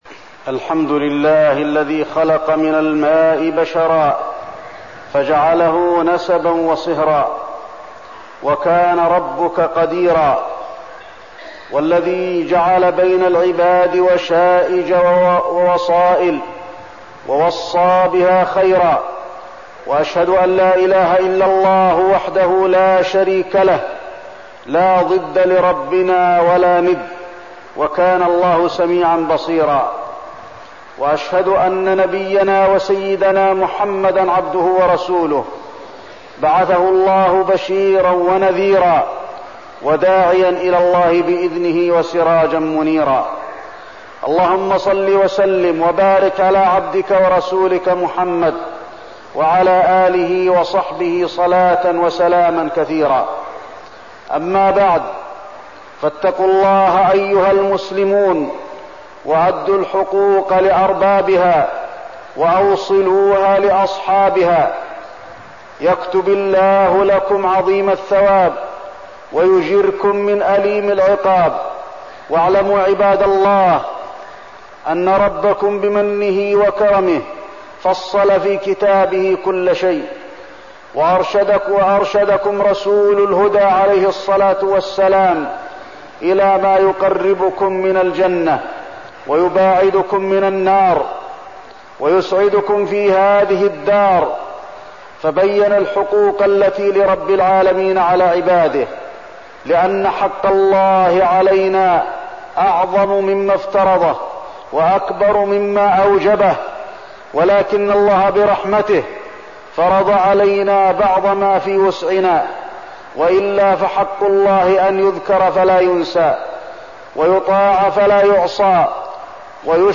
تاريخ النشر ٢٩ محرم ١٤١٥ هـ المكان: المسجد النبوي الشيخ: فضيلة الشيخ د. علي بن عبدالرحمن الحذيفي فضيلة الشيخ د. علي بن عبدالرحمن الحذيفي صلة الرحم